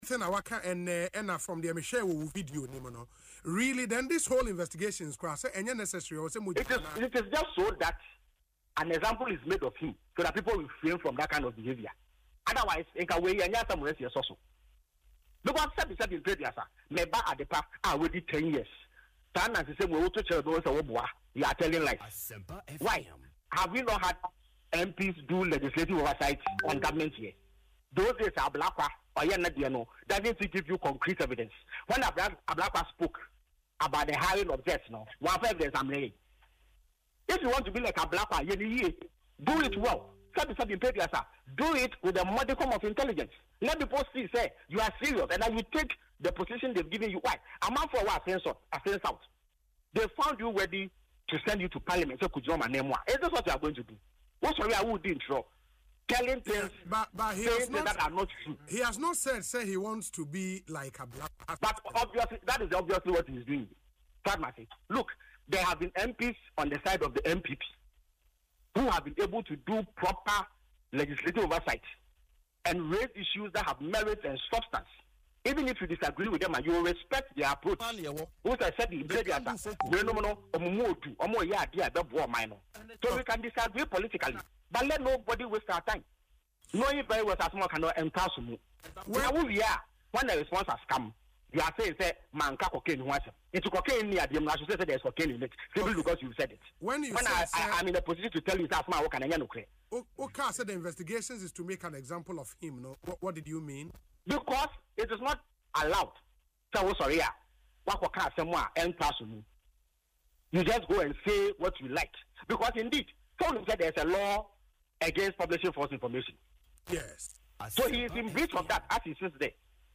In an interview on Asempa FM’s Ekosii Sen, Ofosu advised the former Deputy Education Minister to improve his approach if he intends to be taken seriously like Ablakwa.